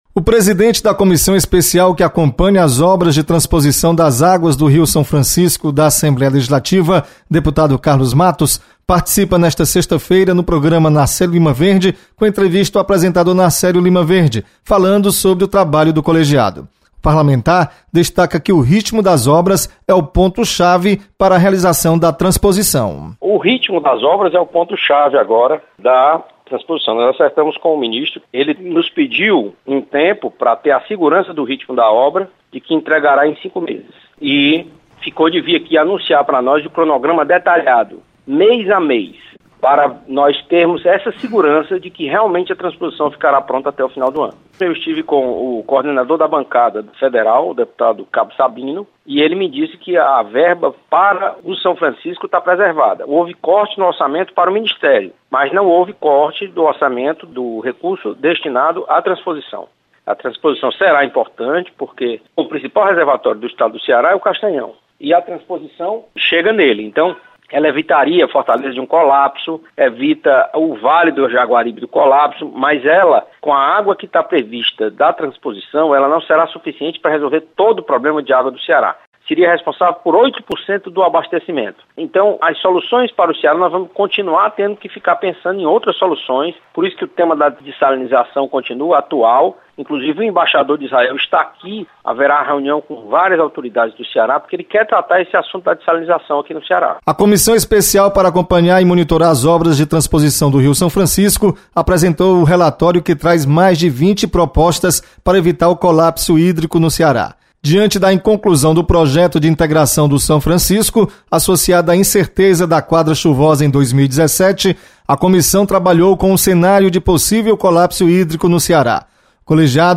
Deputado Carlos Matos informa sobre andamento das obras de transposição das águas do rio São Francisco.